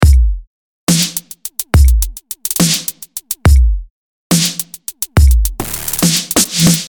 The initial dry loop… unedited
The untreated loop playing back